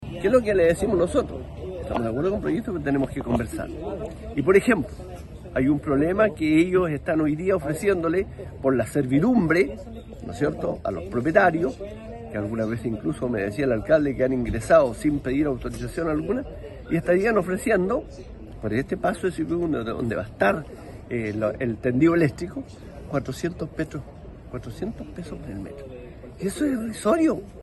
Por su parte, Luis Molina, alcalde de Ninhue y presidente de los alcaldes del Valle del Itata, aseguró que los precios ofrecidos a los propietarios por la empresa a cargo del proyecto Mataquito son irrisorios.